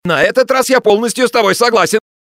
Звуки согласия
Все файлы записаны четко, без фоновых шумов.